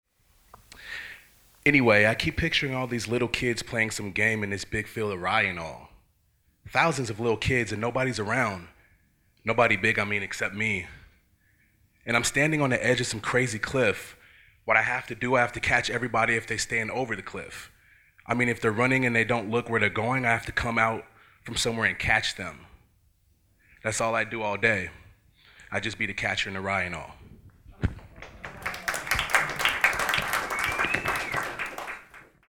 Poetry
My Hero Poetry salon 2015 Excerpt: Catcher in the Rye